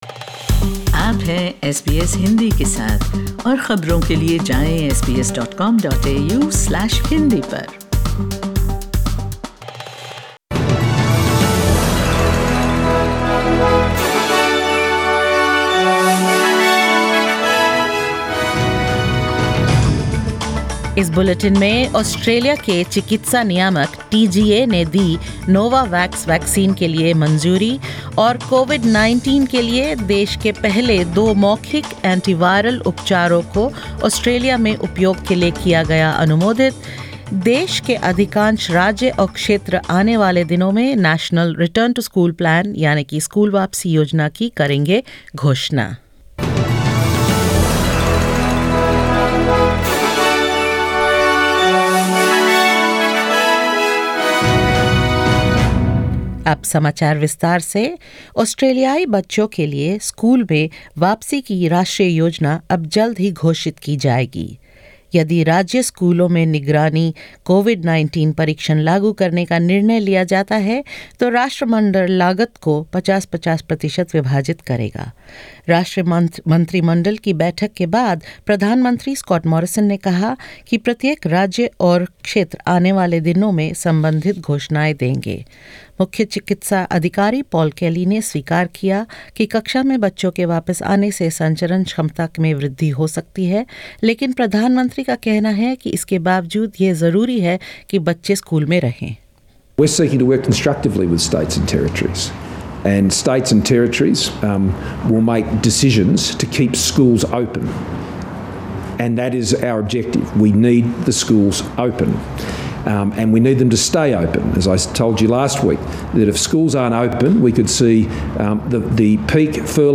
In this latest SBS Hindi bulletin: The Therapeutic Goods Administration (TGA) approves the Novavax vaccine and grants provisional approval to two oral anti-viral treatments for COVID-19; Individual states and territories to announce their return-to-school plans soon and more.